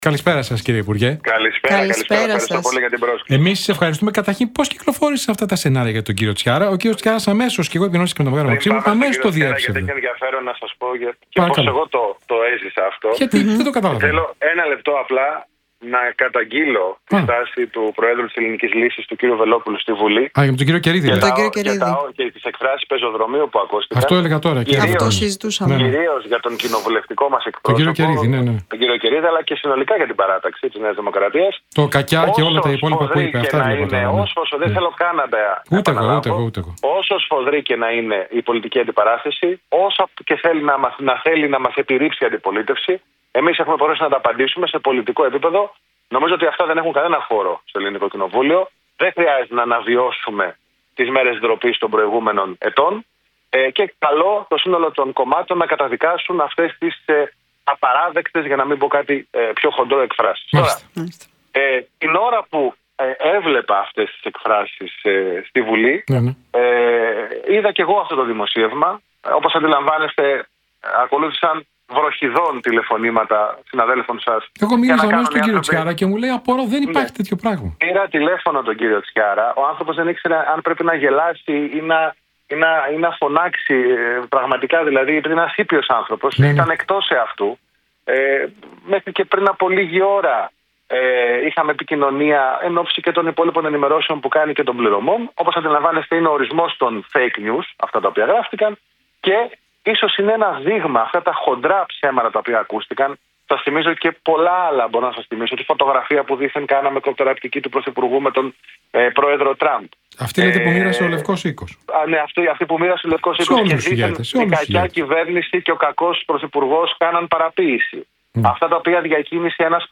Μαρινάκης για Βελόπουλο: Καλώ το σύνολο των κομμάτων να καταδικάσουν αυτές τις απαράδεκτες εκφράσεις Τη στάση του προέδρου της Ελληνικής Λύσης, καυτηρίασε ο κυβερνητικός εκπρόσωπος Παύλος Μαρινάκης σε συνέντευξή του στον Realfm 97,8.